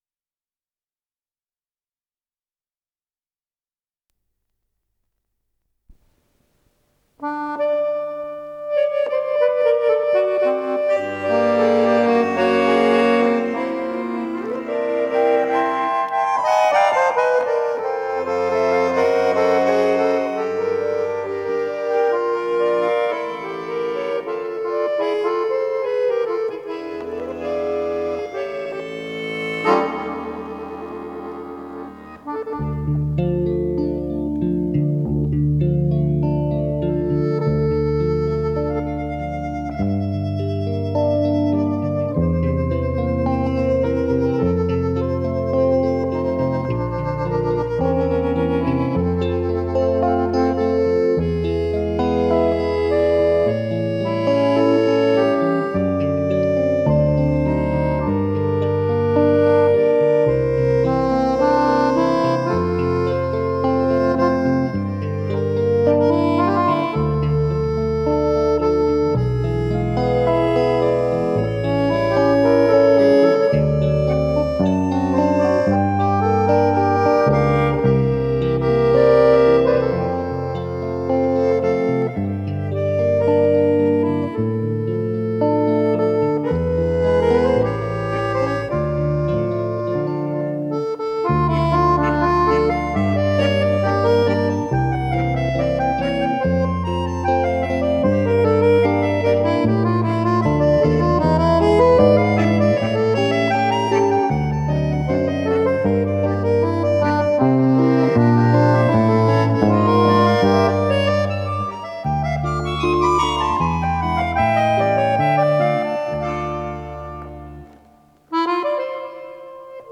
ПодзаголовокРоманс
Скорость ленты38 см/с